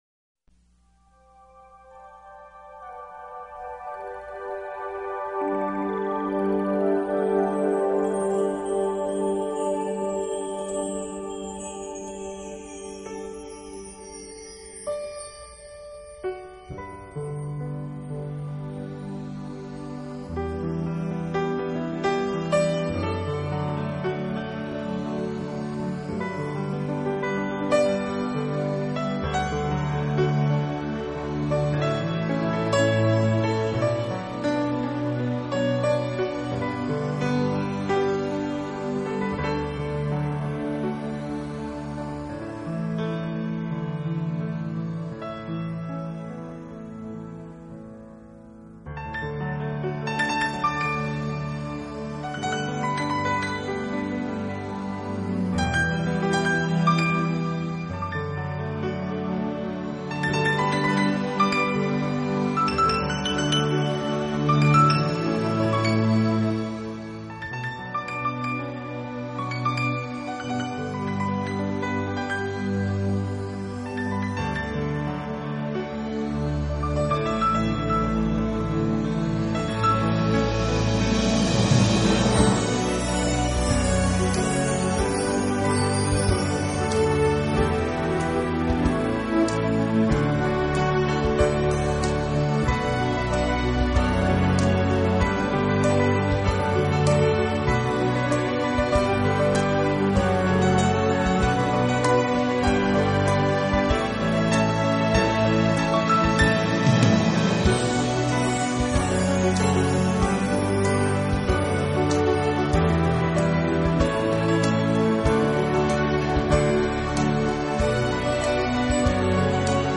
音乐类型: New Age